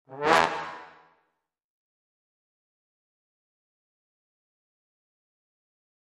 Music Effect; Jazz Trombone Slide Up Into Hit.